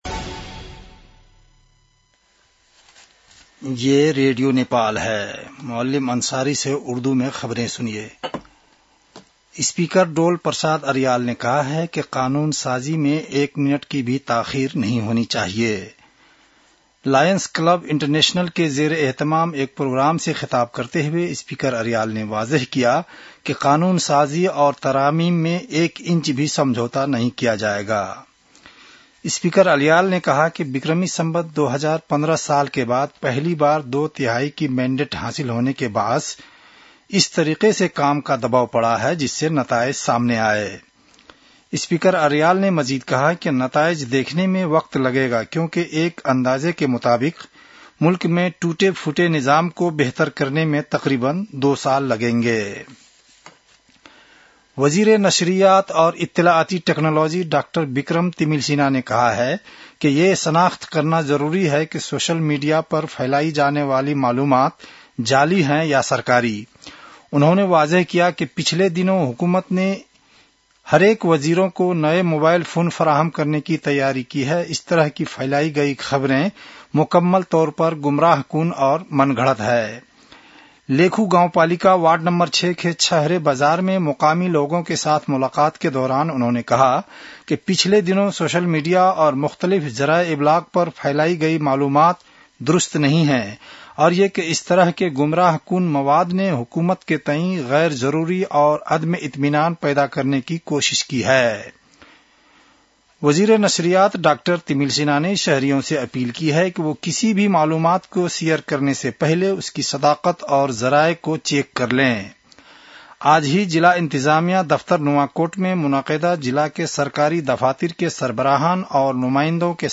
उर्दु भाषामा समाचार : ६ वैशाख , २०८३